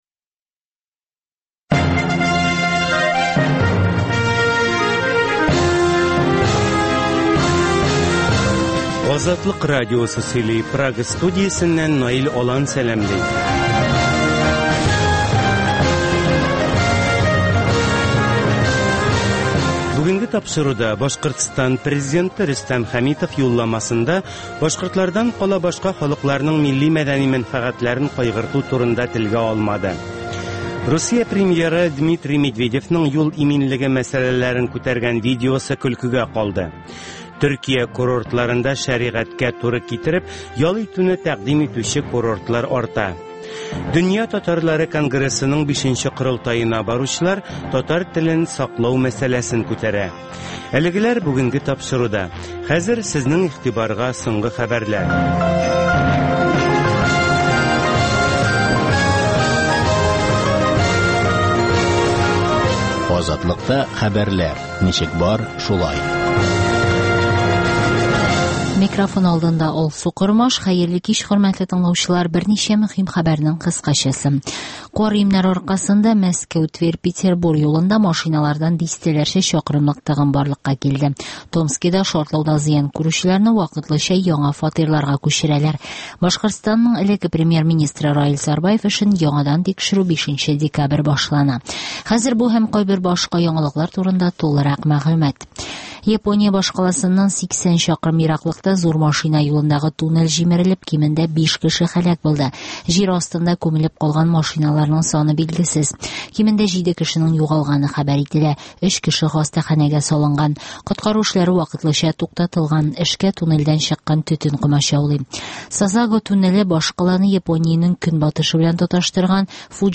Азатлык узган атнага күз сала - соңгы хәбәрләр - башкортстаннан атналык күзәтү - татар дөньясы - түгәрәк өстәл сөйләшүе